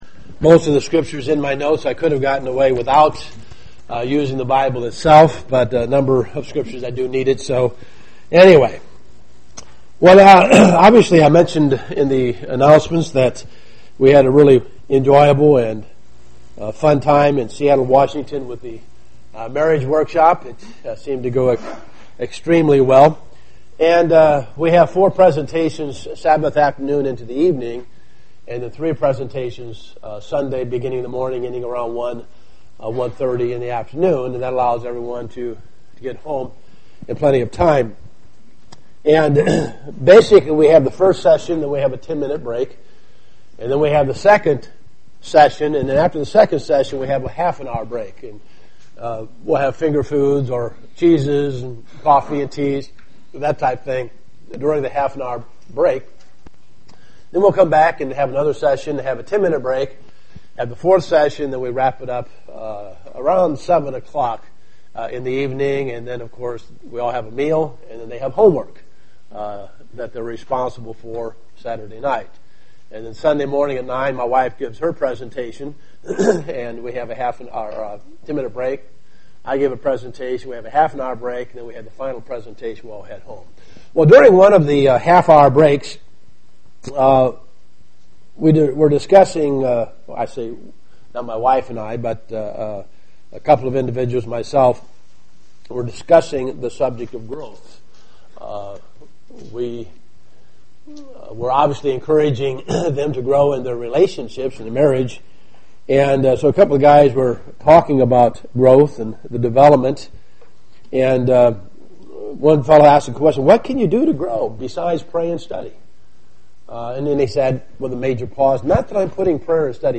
Given in Dayton, OH
UCG Sermon Studying the bible?